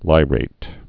(līrāt, -rĭt)